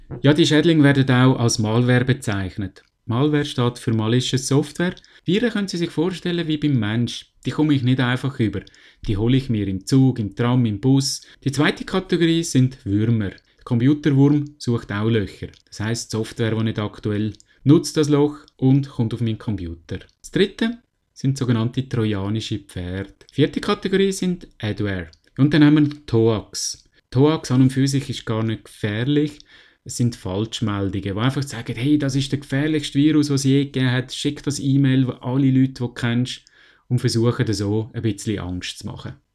Rechts im Archiv gibt es das Interview auch in Schweizerdeutsch!!!